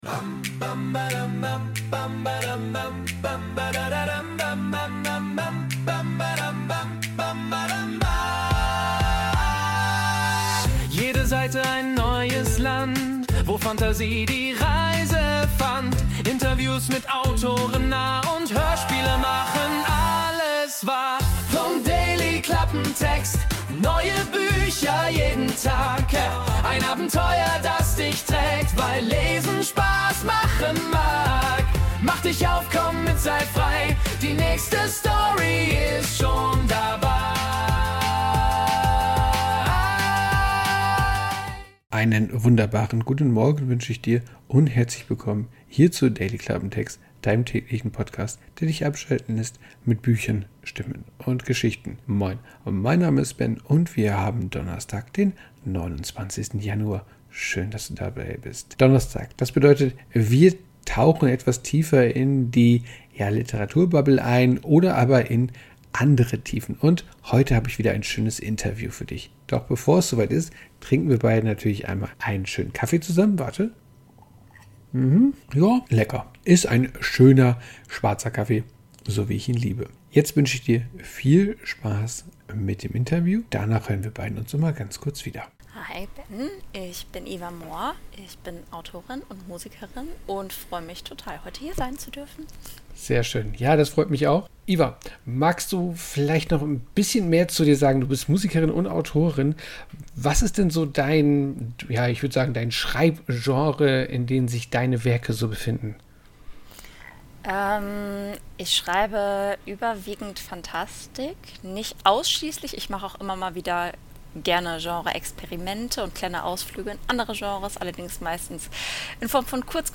Interview ~ Dailyklappentext Podcast
Das wirklich unterhaltsame und sehr nette Gespräch habe ich dir heute mitgebracht.